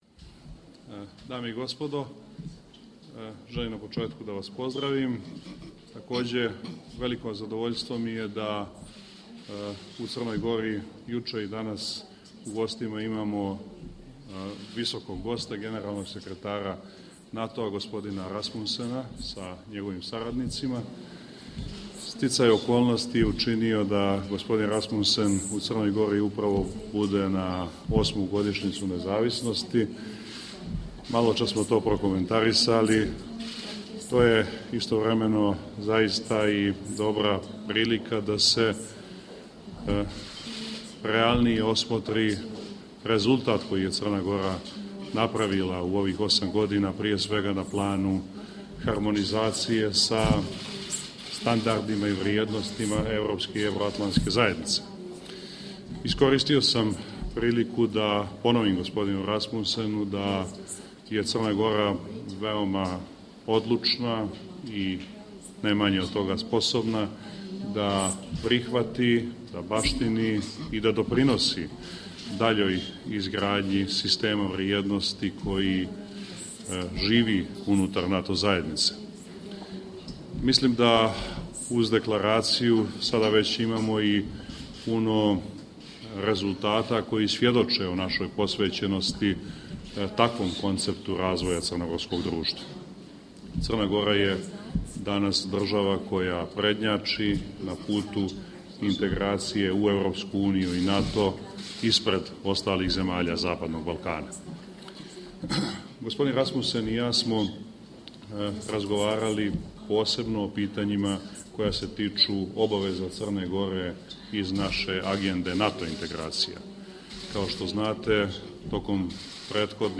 Opening remarks by NATO Secretary General Anders Fogh Rasmussen at the joint press point with the Prime Minister of Montenegro, Milo Djukanovic
Audio Joint press point with NATO Secretary General Anders Fogh Rasmussen and the Prime Minister of Montenegro, Milo Djukanovic 22 May. 2014 | download mp3 News NATO Secretary General welcomes Montenegro's achievements, encourages further reforms 22 May. 2014